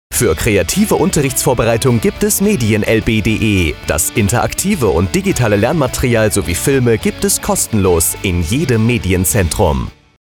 Tonspot von MedienLB:
Funkspot_MedienLB-Medien-fuer-Lehrplaene-und-Bildungsstandards-GmbH-10-Sek-.mp3